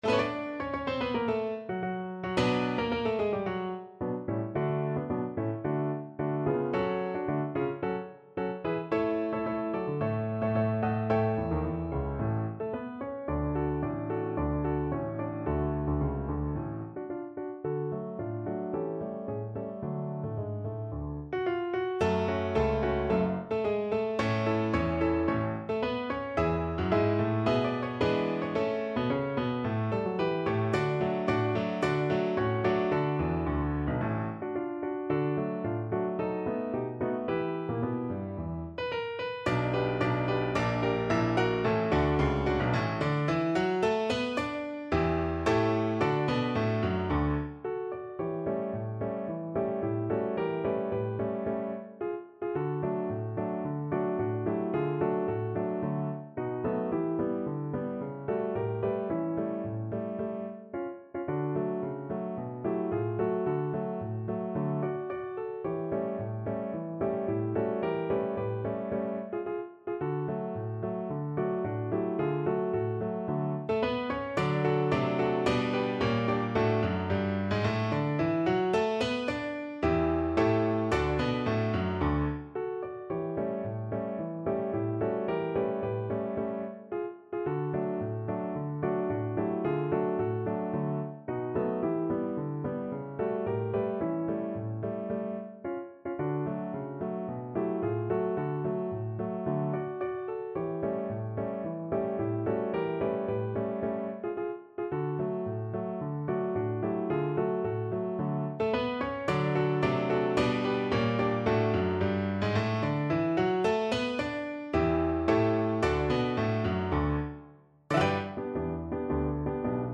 Classical Alford, Kenneth J. The Great Little Army Piano version
No parts available for this pieces as it is for solo piano.
D major (Sounding Pitch) (View more D major Music for Piano )
2/2 (View more 2/2 Music)
Piano  (View more Intermediate Piano Music)
Classical (View more Classical Piano Music)